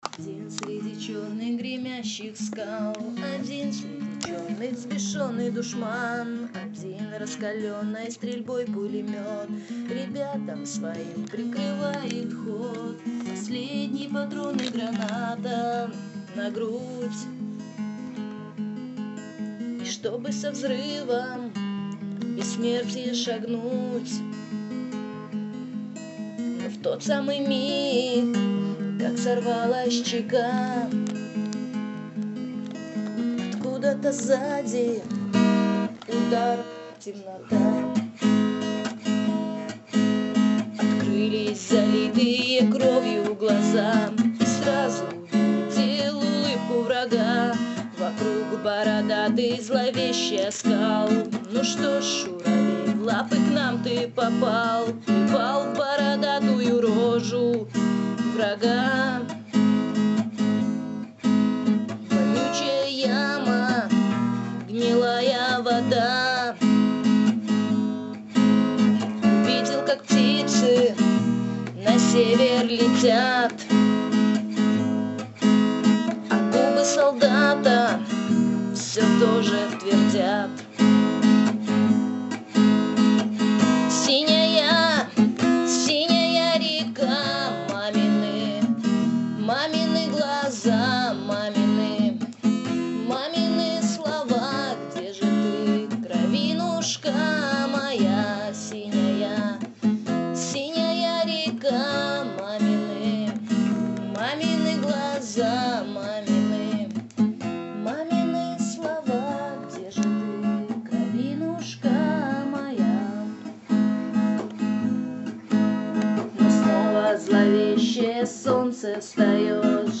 армейские